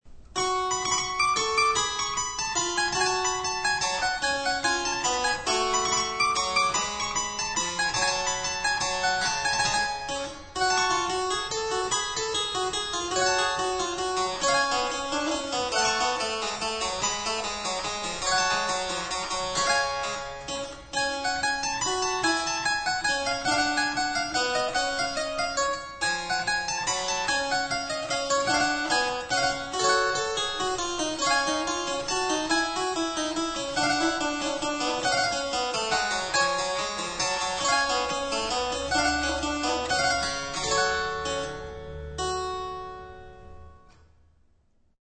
EPINETTE FLAMANDE
Elle sonne comme l'original en 4 pieds et il faut de bien petites mains pour jouer sur le clavier très court... Un exercice pour travailler la précision du jeu.
Vous pouvez écouter cette épinette dans un Menuet de E. Jacquet de la Guerre en cliquant ici.